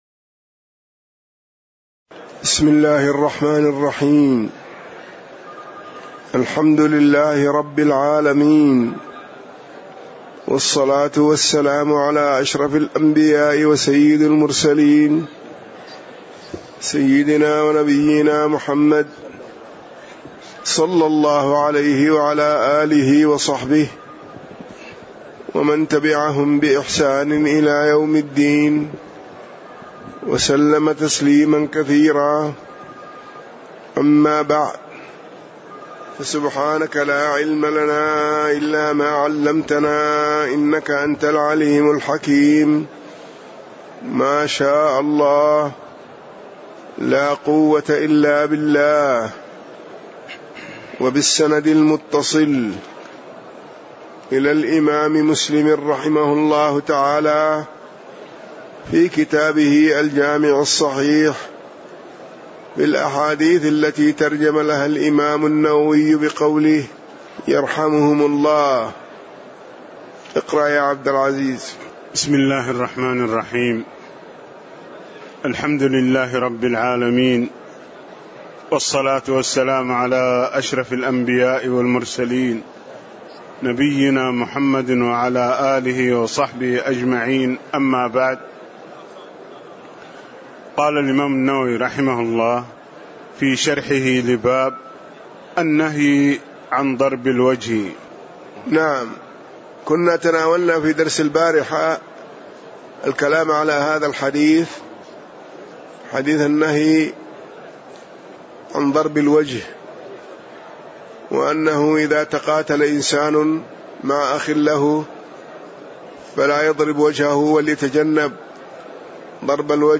تاريخ النشر ١٤ صفر ١٤٣٨ هـ المكان: المسجد النبوي الشيخ